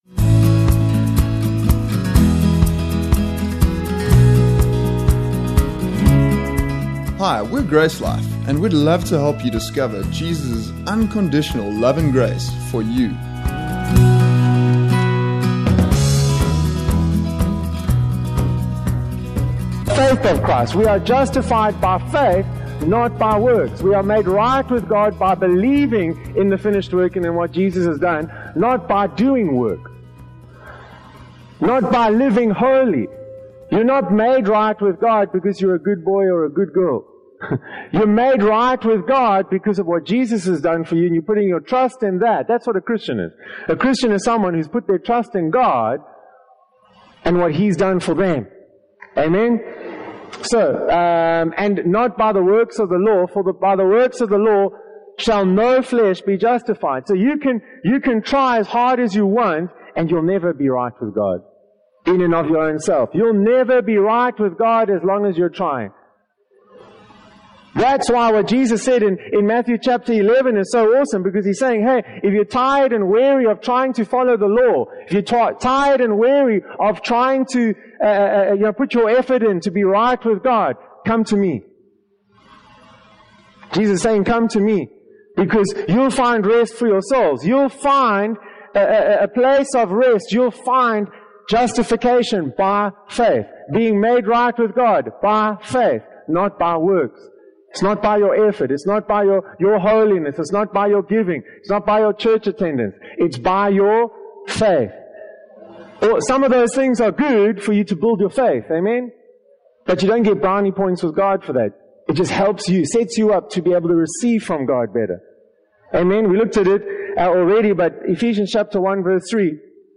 The Freedom Conference: How To Experience The Power Of God